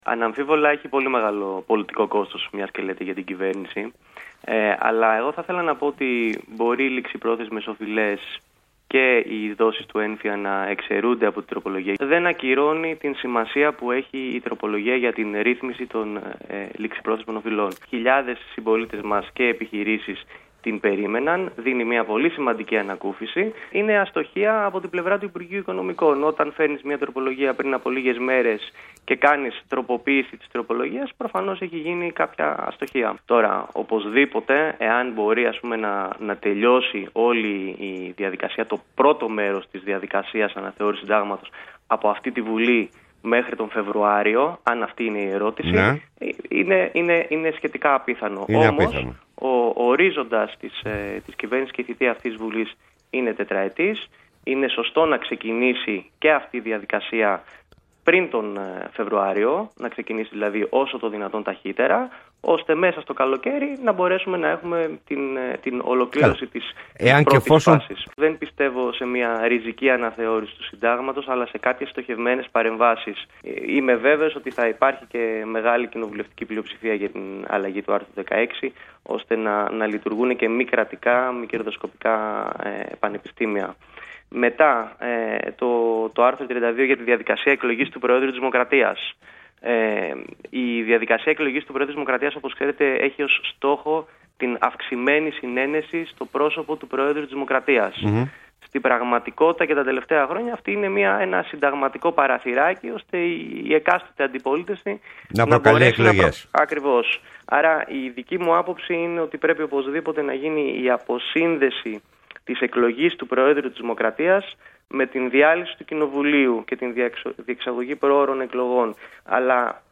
Συνέντευξη στον Ρ/Σ Βήμα fm για τη συνταγματική αναθεώρηση και τον ΕΝΦΙΑ